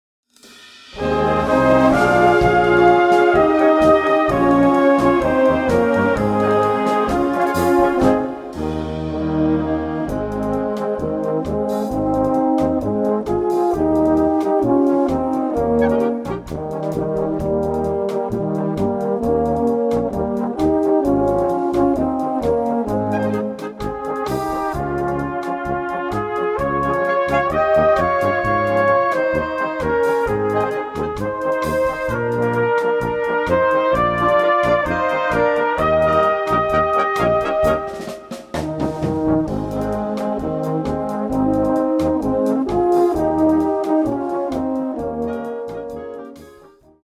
Gattung: Beguine
Besetzung: Blasorchester
Eine neue, gefühlvolle Beguine